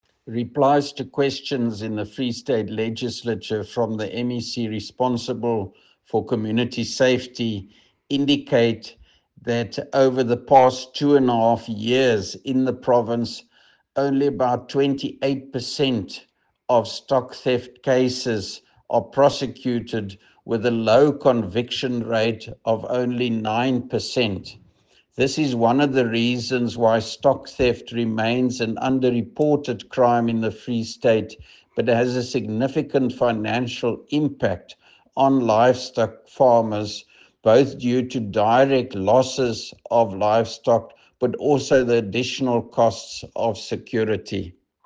Afrikaans soundbites by Roy Jankielsohn MPL and Sesotho soundbite by Jafta Mokoena MPL.